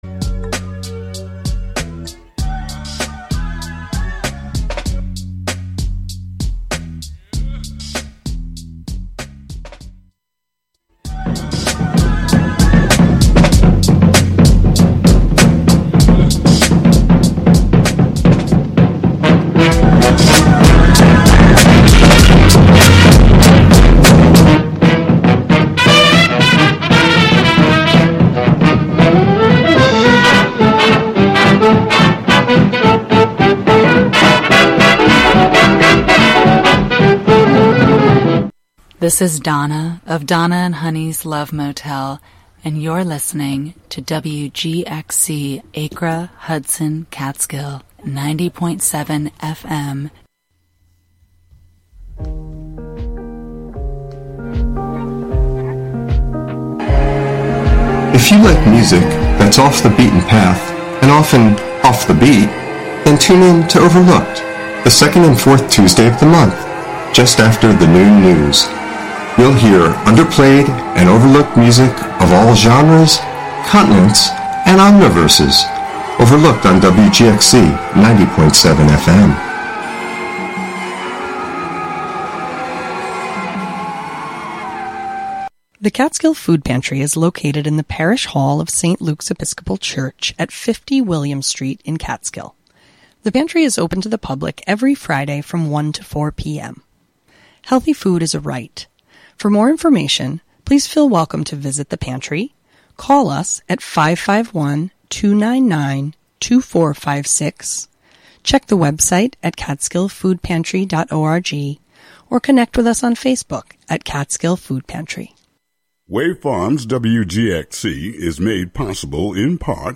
Hosted by various WGXC Volunteer Programmers.
Tune in for special fundraising broadcasts with WGXC Volunteer Programmers!